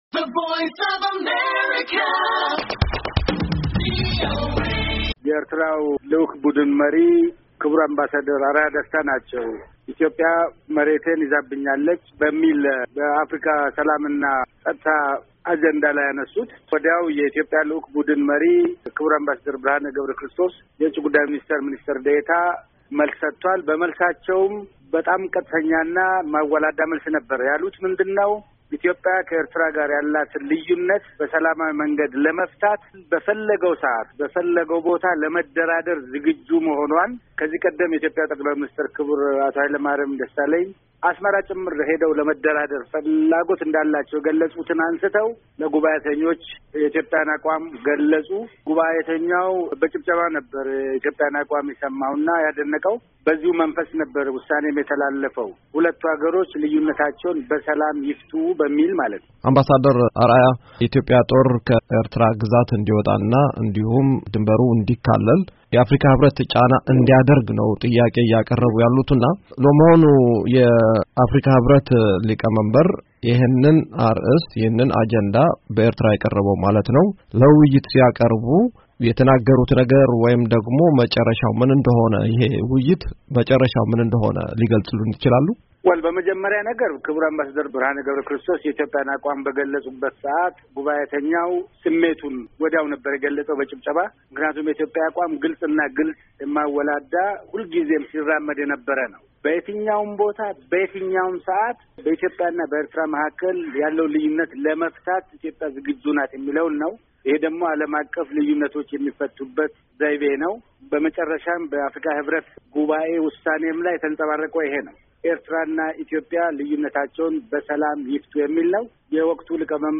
እንደገና የሞቀው የኢትዮጵያና የኤርትራ የድንበር ጉዳይ እሰጥ አገባ - ዘገባ የሚጫወቱ ወይም የተጫወቱ ፕሮግራሞች ዝርዝር ቅዳልኝ ከአምባሣደር ግርማ አስመሮም ጋር ለተደረገው ቃለምልልስ ከታች ያለውን...